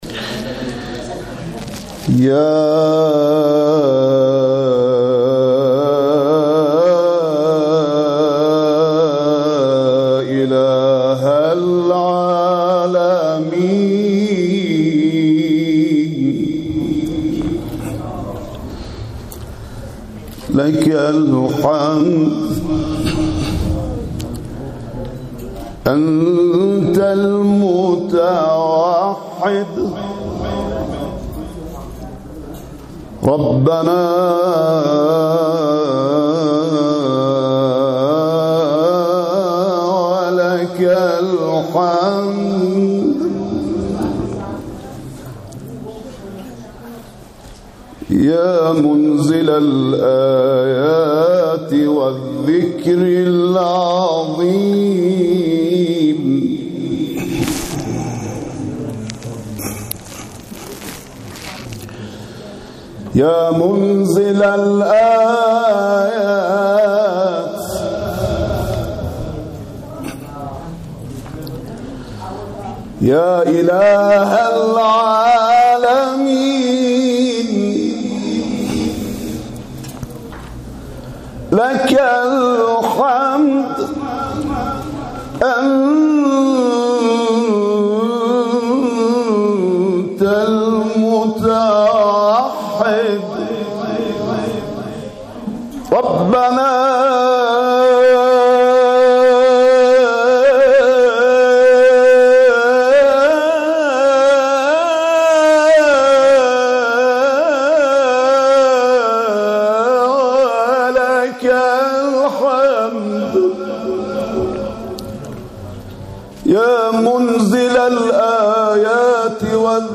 در محفل انس با قرآن
ابتهال خوانی